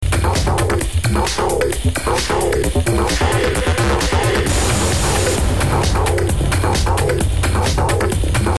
Techno/Acid ID